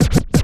scratch01.wav